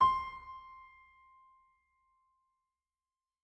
piano-sounds-dev
c5.mp3